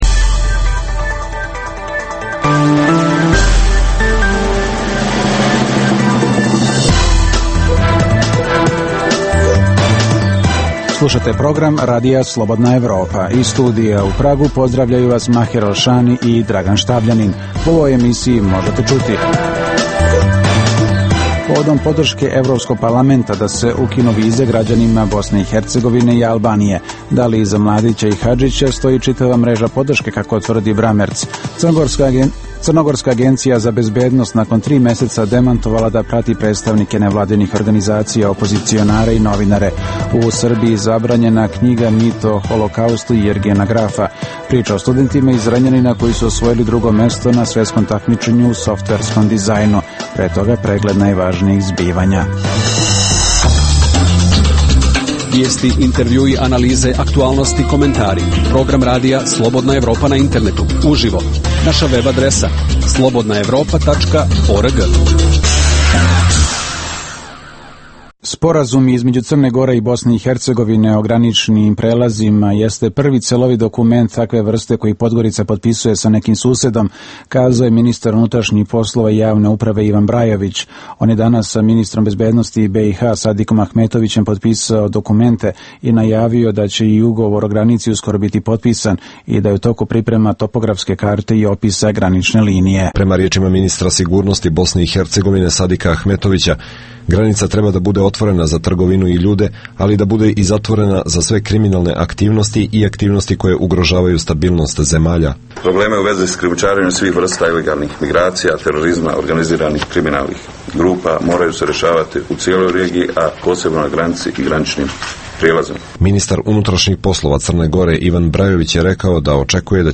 Reportaže iz svakodnevnog života ljudi su svakodnevno takođe sastavni dio “Dokumenata dana”.